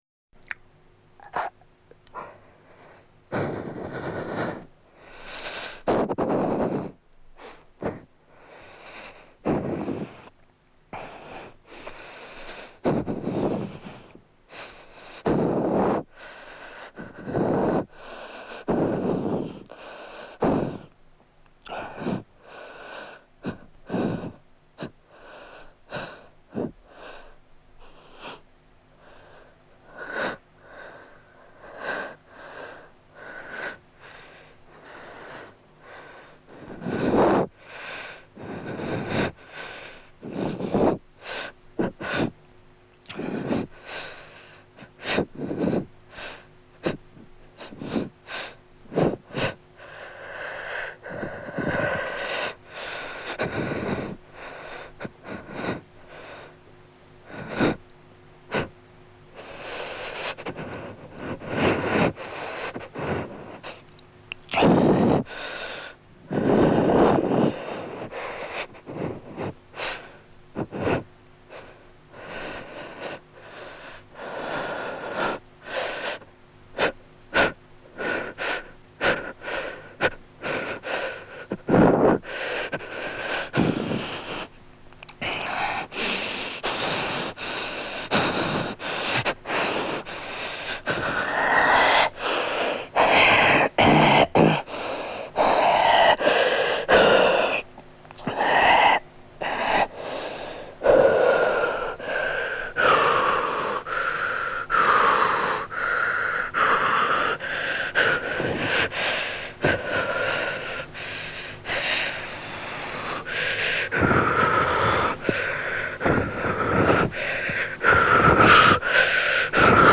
Gil J. Wolman, La Mémoire, Mégapneumes, 1967, poème sonore publié dans la revue « ou », n°33, 1968
De ces expérimentations, les rares enregistrements qui demeurent donnent à entendre un langage primal fait de sons désarticulés, spasmes et râles étouffés.